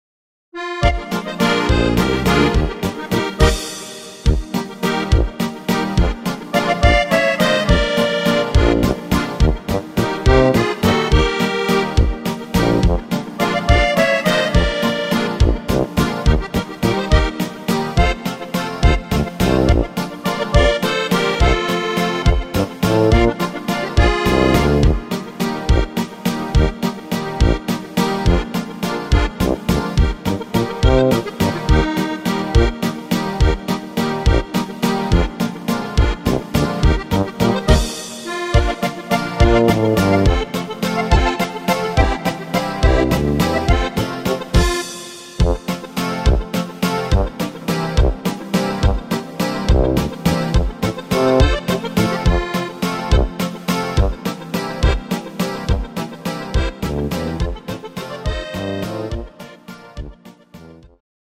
Kurzmedley